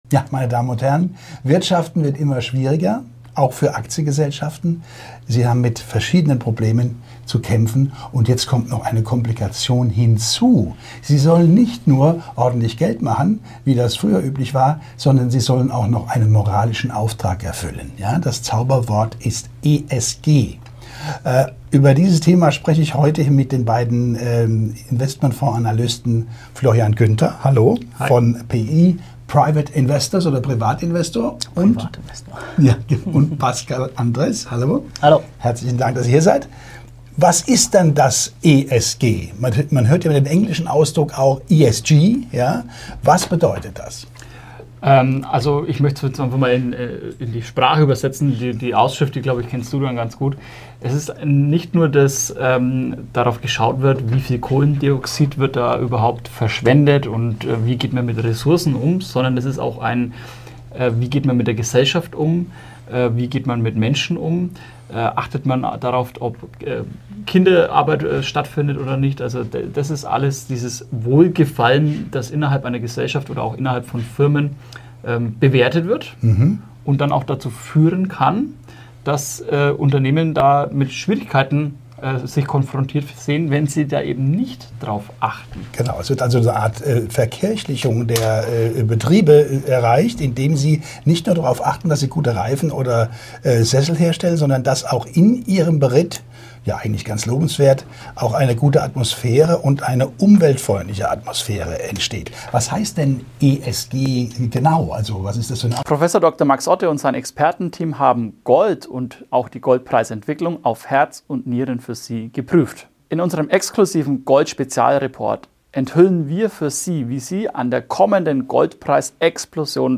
In diesem Gespräch enthüllen Investment-Experten